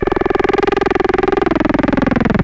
Loudest frequency 655 Hz Recorded with monotron delay and monotron - analogue ribbon synthesizer